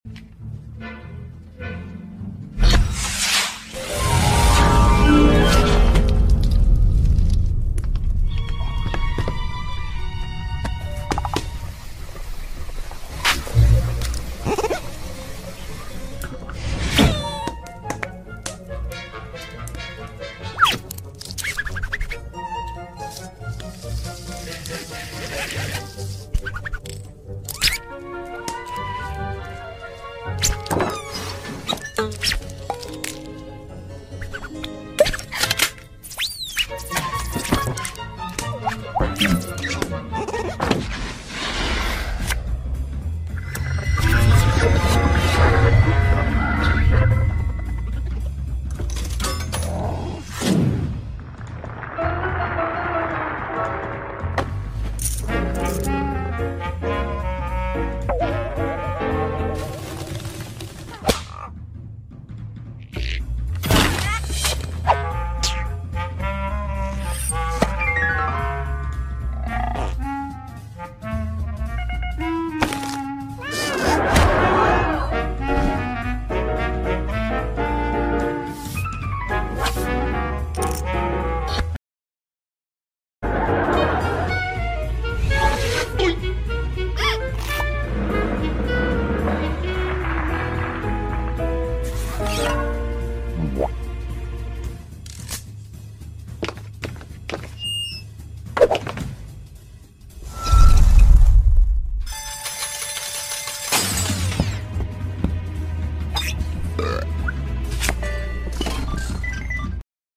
I love doing Husk’s voice.